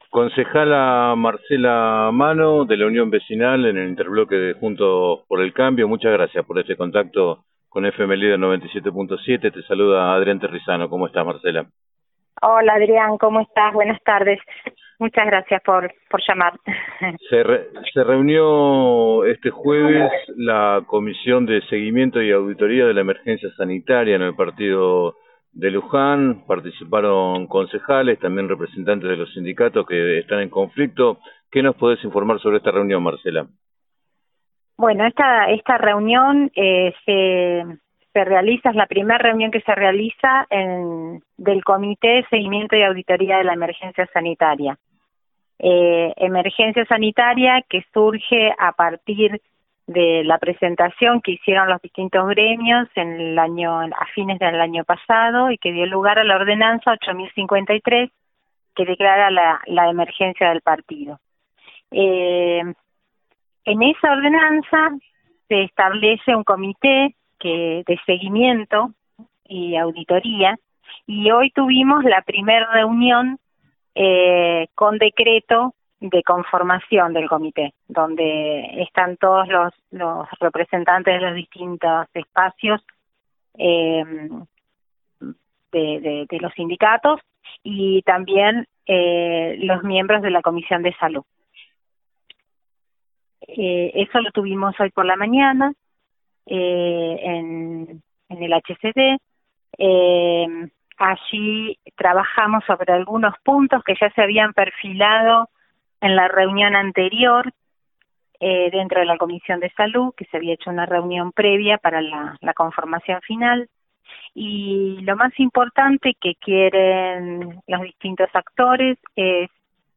En declaraciones al programa 7 a 9 de FM Líder 97.7, la concejala Marcela Manno, de la Unión Vecinal en el Interbloque “Juntos por el Cambio”, expresó que los ediles tienen la voluntad de acercar a las partes ante la suspensión del diálogo entre los gremios y el gobierno municipal.